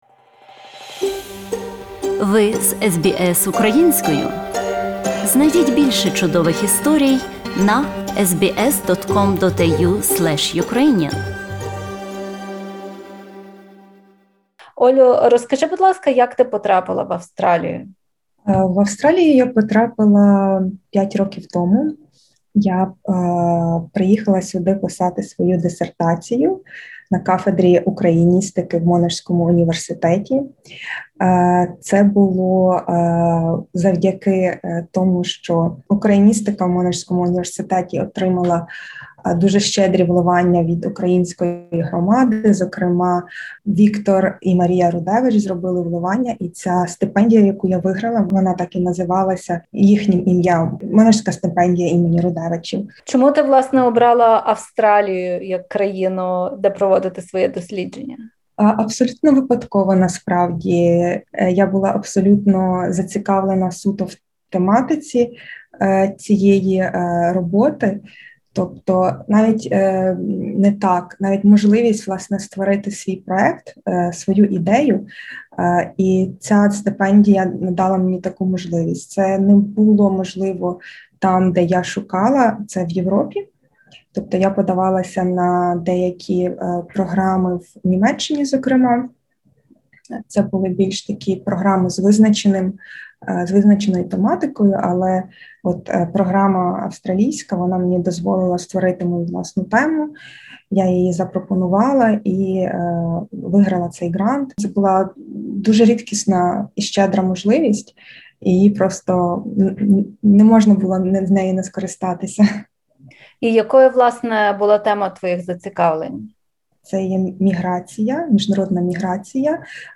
Розмова